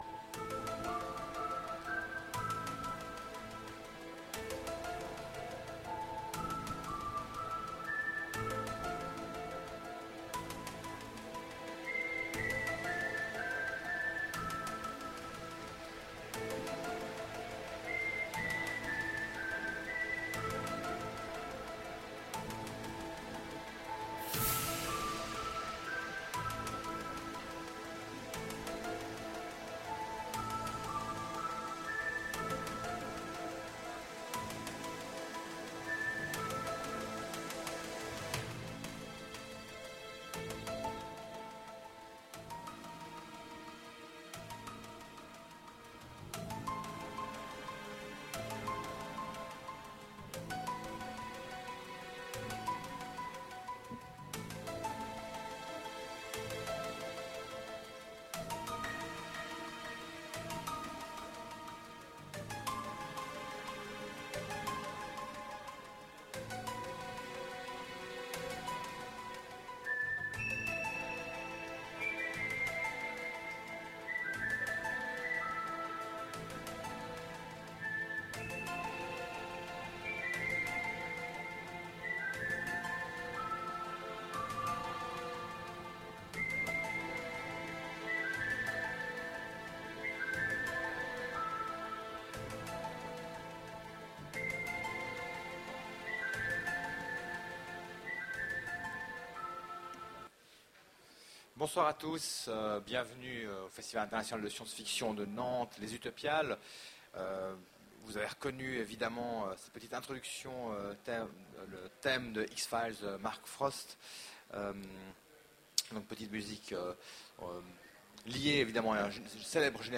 Voici l'enregistrement de la conférence Musique(s) et SF aux Utopiales 2010.